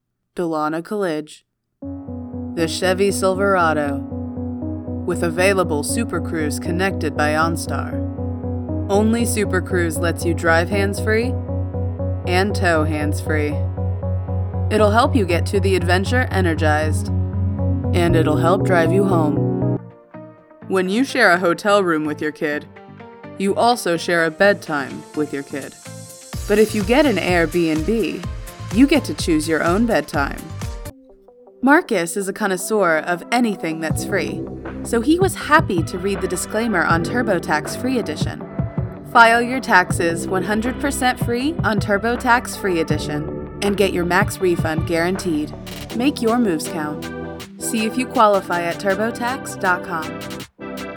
Commercial Reel
American, Southern American